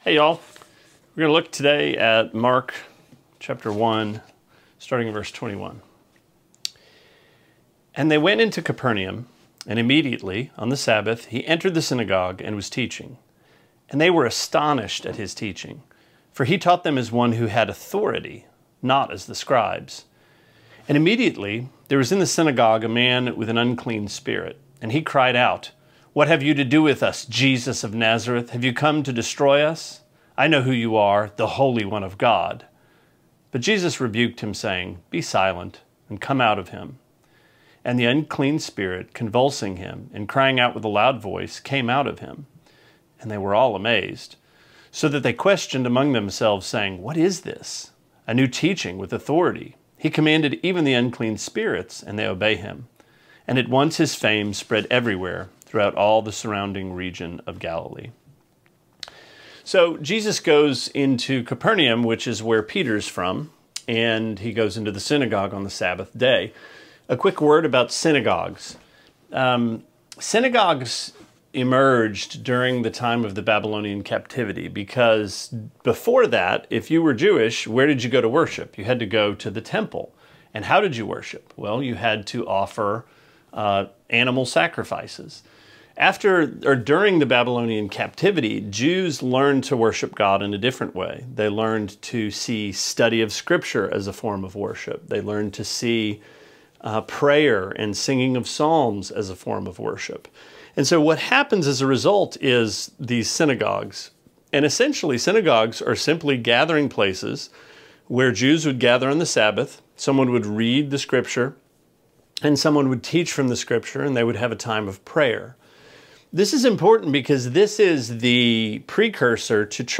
Sermonette 6/14: Mark 1:21-28: Authority